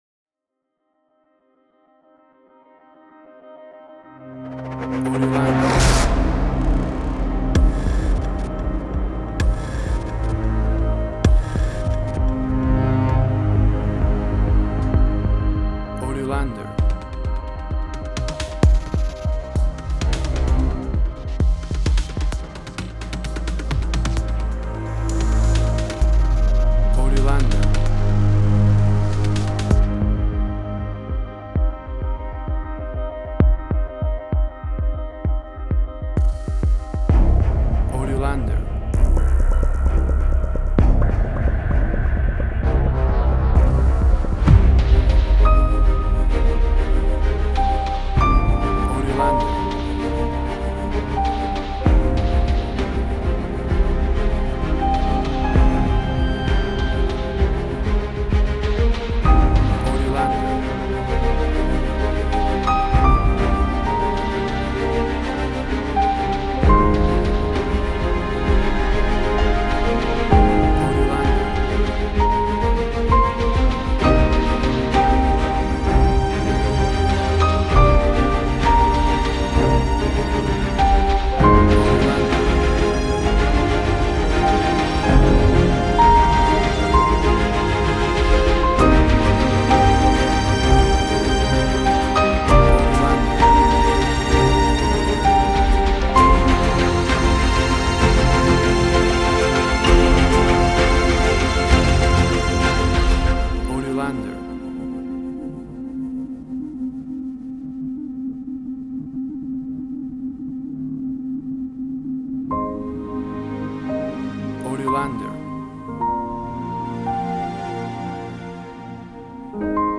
Science fiction, electronic environment.
Tempo (BPM): 130